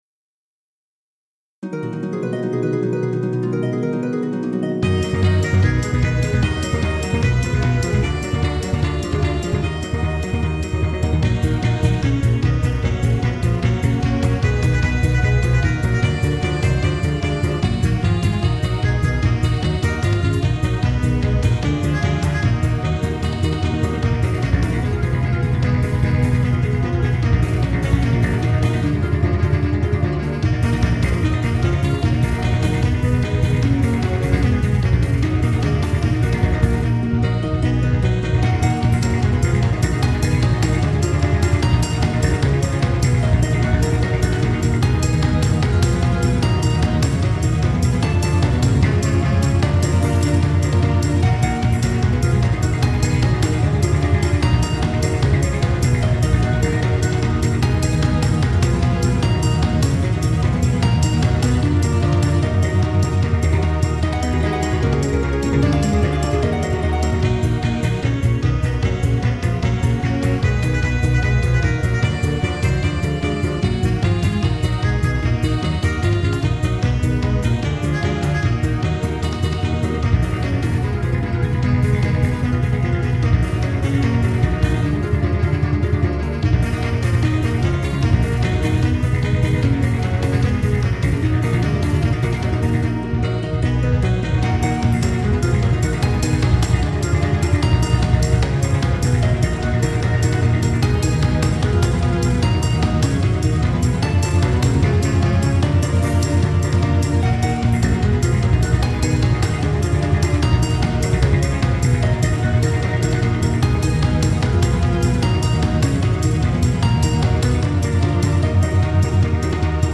〜オフボーカル版〜